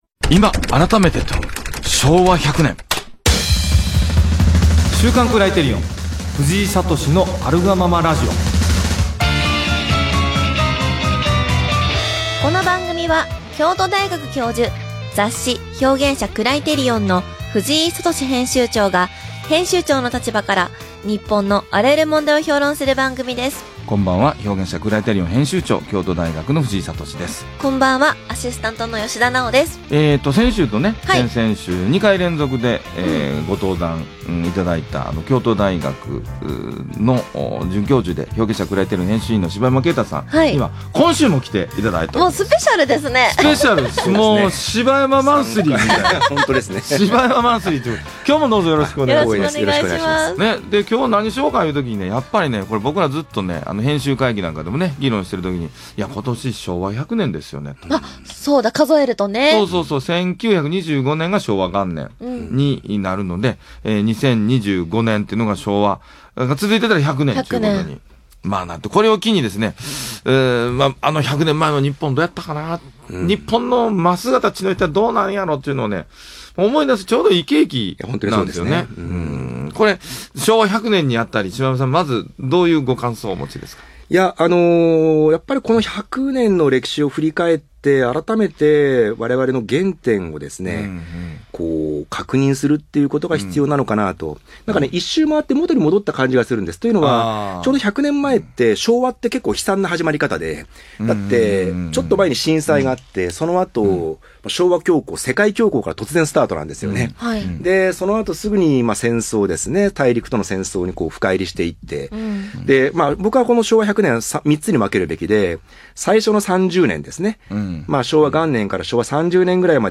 【ラジオ】今改めて問う「昭和100年」